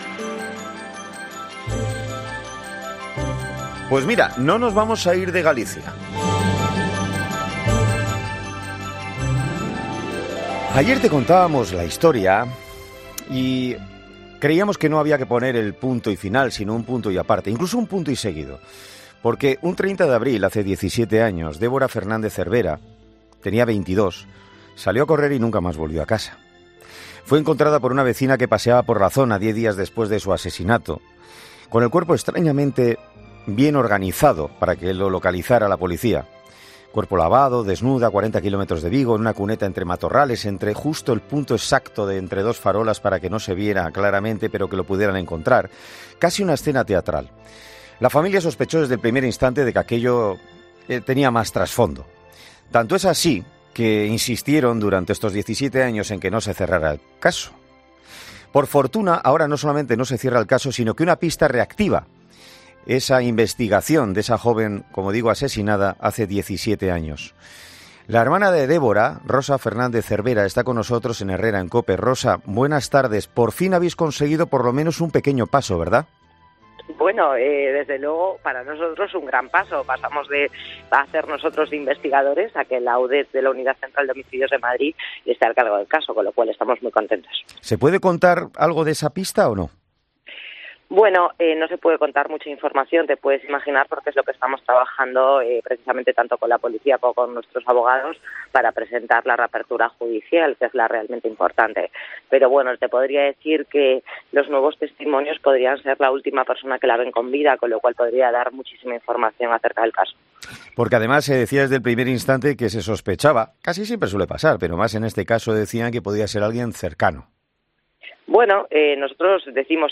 Para conocer todos los detalles, este martes ha sido entrevistada en 'Herrera en COPE' su hermana